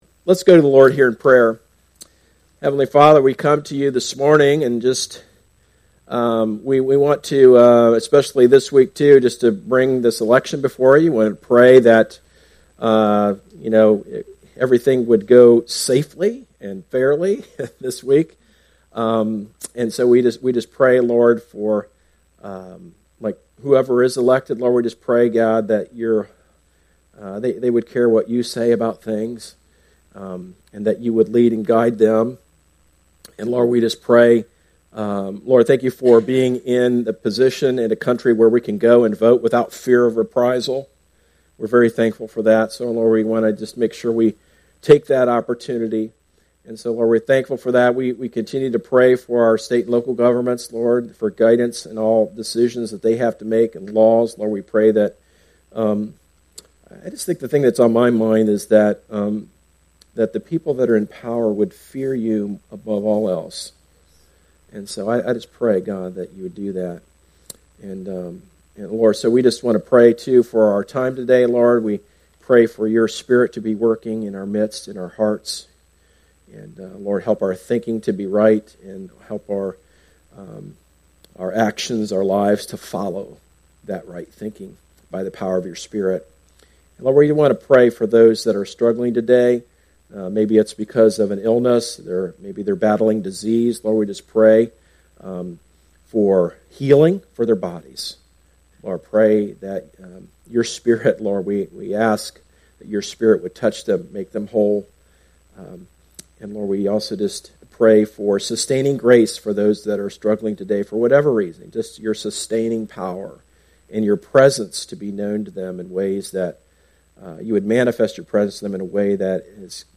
Sermons - Darby Creek Church - Galloway, OH